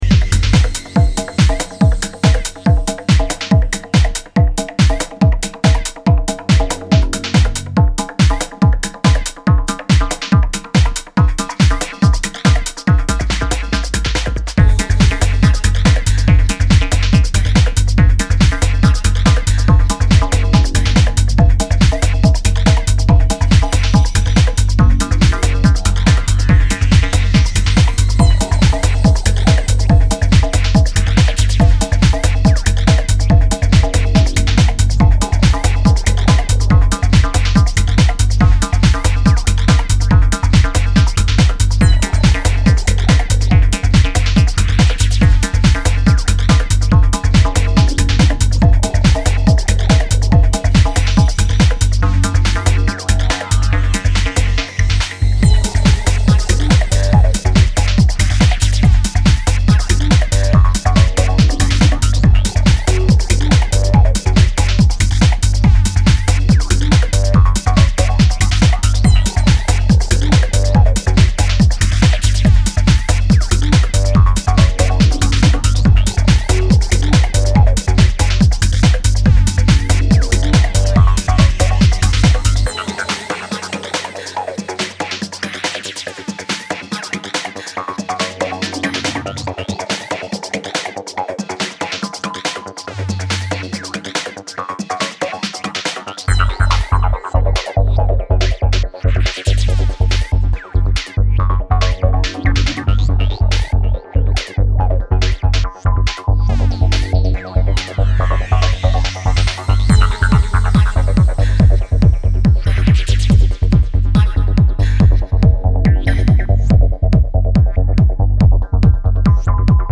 Technical and tribal in equal doses from a forgotten time